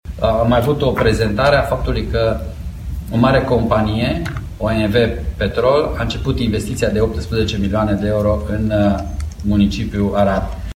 Primarul Aradului, Gheorghe Falcă, a anunţat că OMV Petrom a început investiţia de 18 milioane de euro în municipiul de pe Mureş.
02-Gheorghe-Falca-OMV.mp3